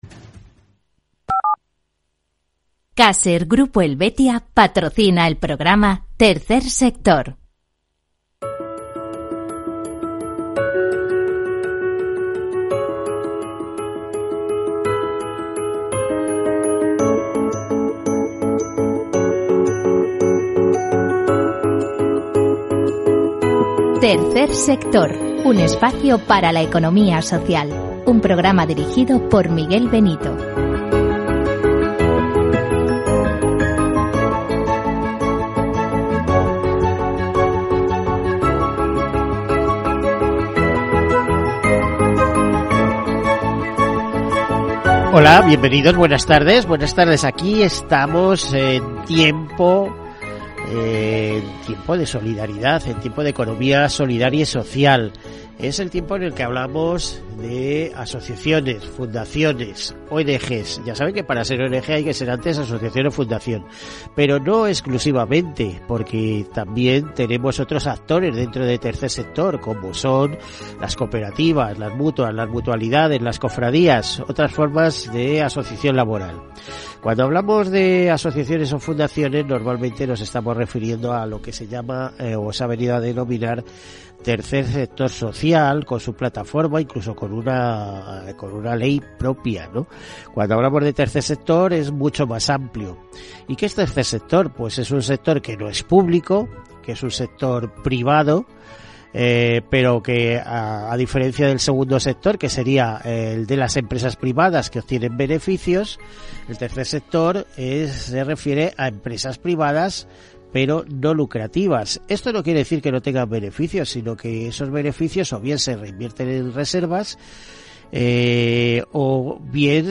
El programa dedicado a la economía solidaria y social. Información y entrevistas sobre fundaciones, asociaciones, ONG, cooperativas, mutuas, mutualidades, iniciativas de RSC, etcétera, que actúan con ausencia de lucro ante pequeños y grandes temas de marcado interés general.